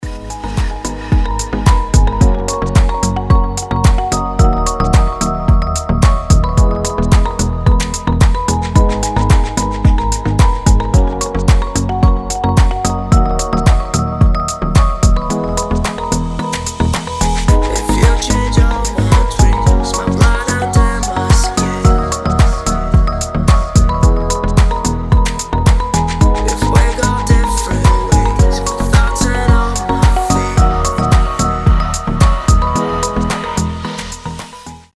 мужской голос
deep house
красивая мелодия
nu disco
колокольчики
Стиль: nu disco, deep house